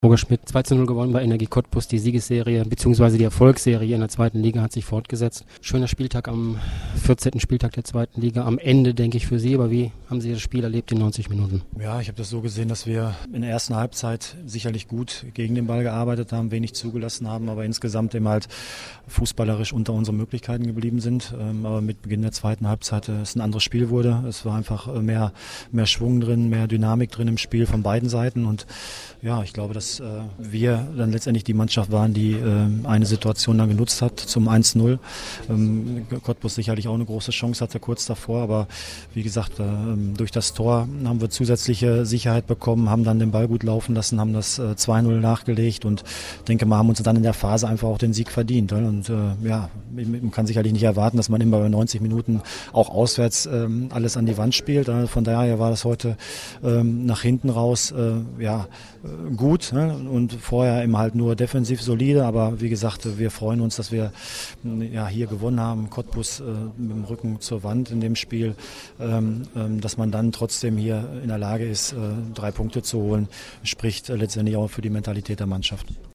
Audiokommentar
Chef-Trainer Roger Schmidt zum Spiel